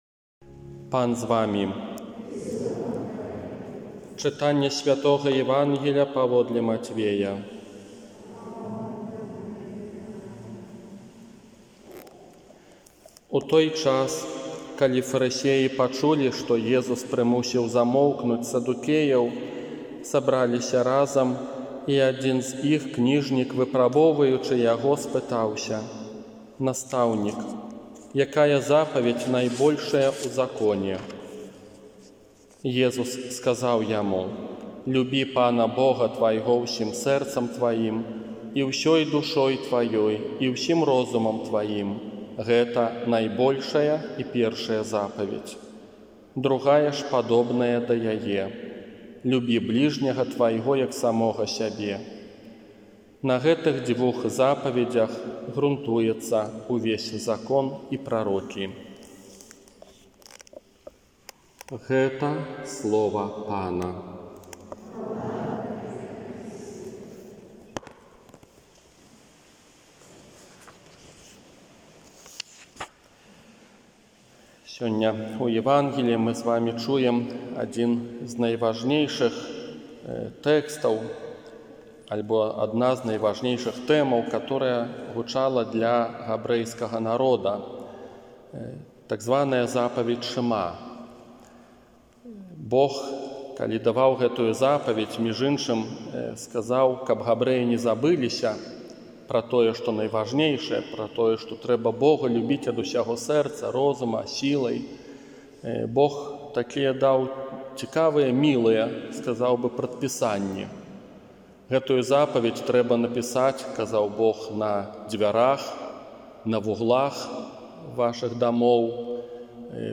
Казанне на трыццатую звычайную нядзелю 25 кастрычніка 2020 года